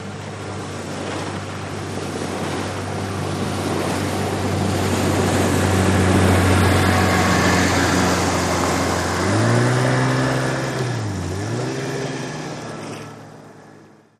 20 hp Johnson Boat Pass By, Slow